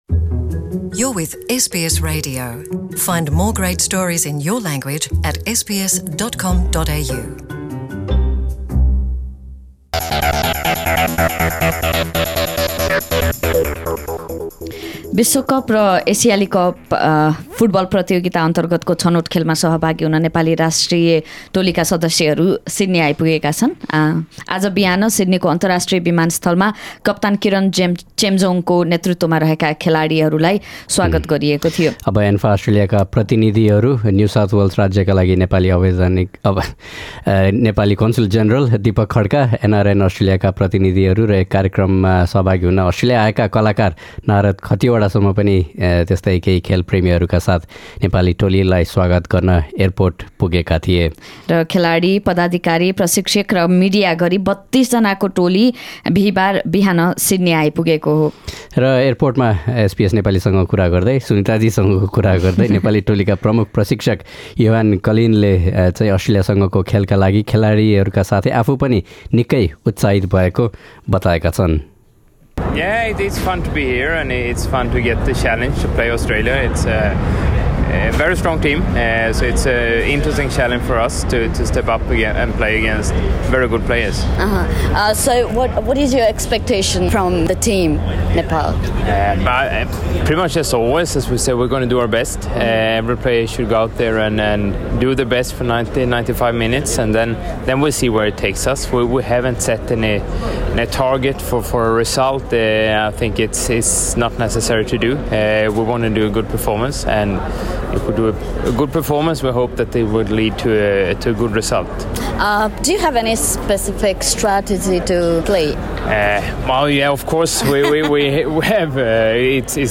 यसबारे, अस्ट्रेलिया नेपाल फुटबल सङ्गठन र गैर आवासीय नेपाली सङ्घ अस्ट्रेलियाका प्रतिनिधिहरूसँग पनि हामीले कुराकानी गरेका छौँ।